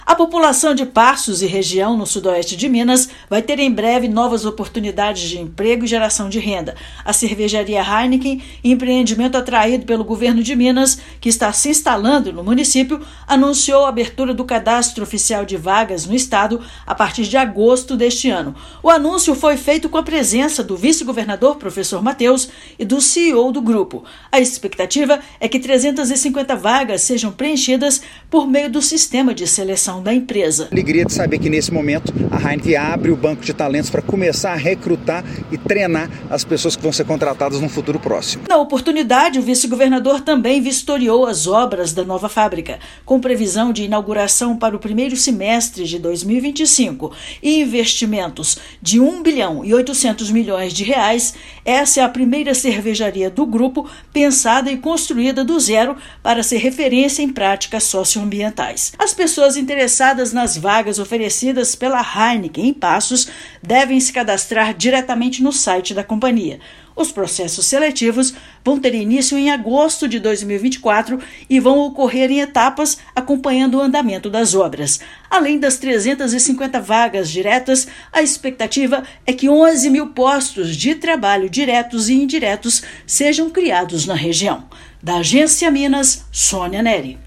[RÁDIO] Cervejaria anuncia banco de vagas de emprego em Passos, no Sudoeste de Minas
Obras da nova unidade da cervejaria Heineken estão em andamento e a expectativa é de inauguração em 2025. Ouça matéria de rádio.